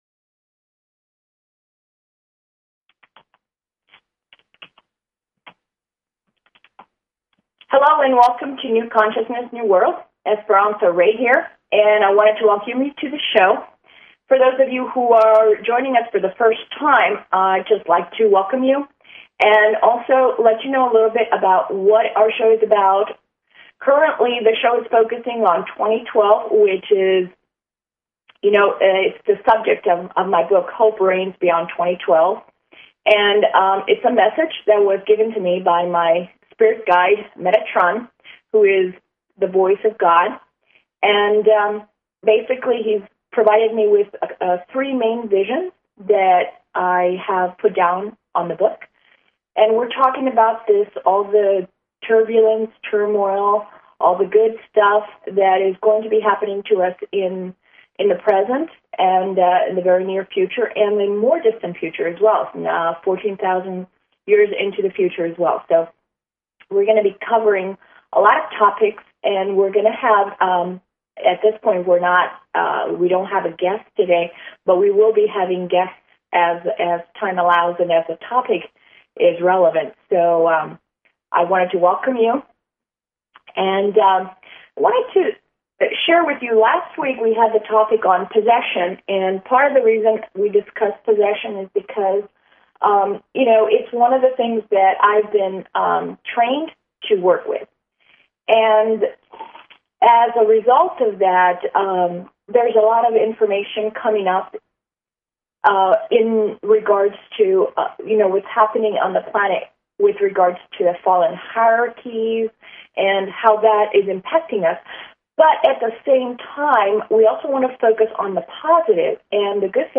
This will be accomplished through a series of discussion, interviews with other experts, teaching techniques to help in this process, and audience participation through questions and answers.